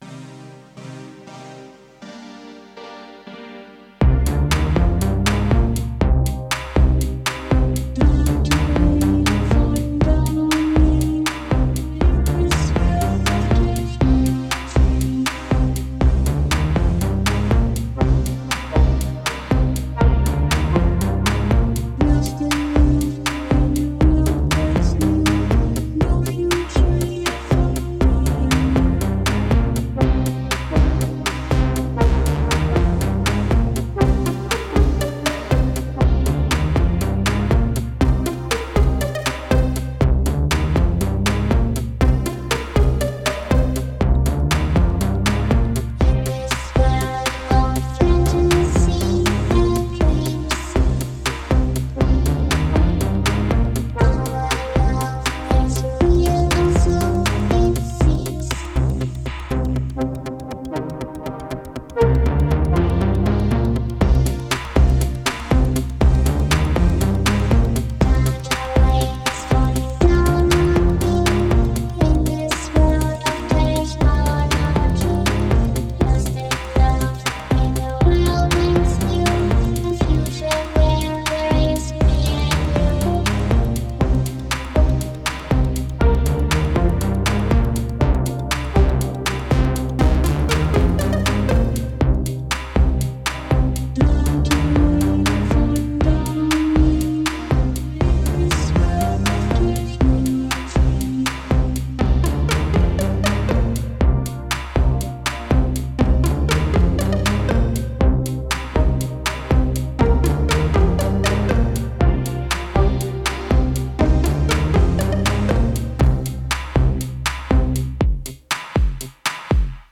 a heavy dose of melodic, vocoded vocals
Dreamy, lush, and full of character.